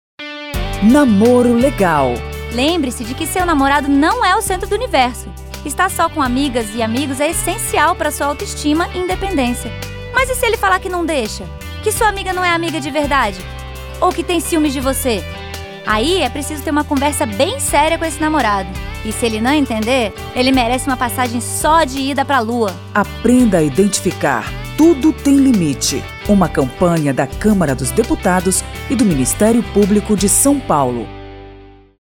São dezoito spots que falam sobre relacionamentos tóxicos.